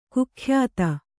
♪ kukhyāta